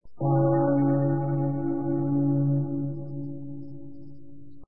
CAMPANAS TOLLBELL
Tonos gratis para tu telefono – NUEVOS EFECTOS DE SONIDO DE AMBIENTE de CAMPANAS TOLLBELL
Campanas_tollbell.mp3